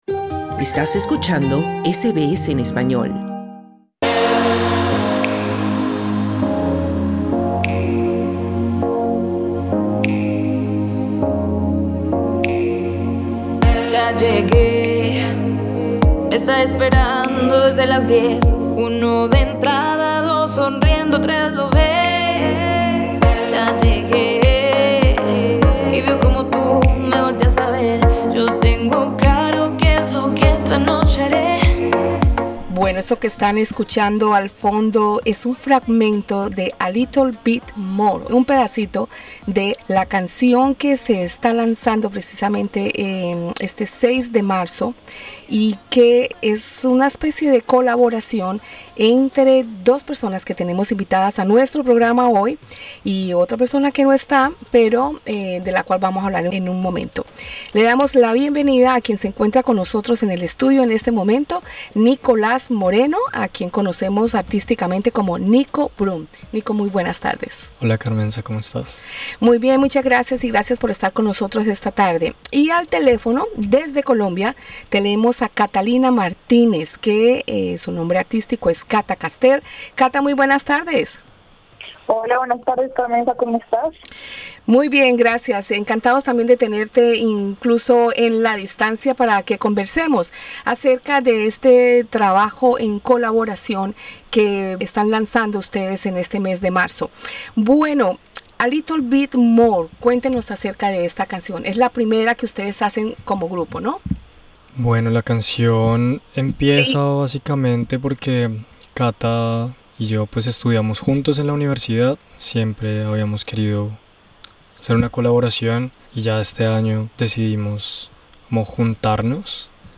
Del experimento nace la primera canción: “A Little bit More”, de la que nos hablan en la entrevista para SBS español.